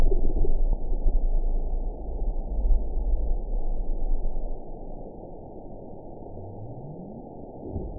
event 922637 date 02/13/25 time 23:42:50 GMT (2 months, 2 weeks ago) score 9.15 location TSS-AB10 detected by nrw target species NRW annotations +NRW Spectrogram: Frequency (kHz) vs. Time (s) audio not available .wav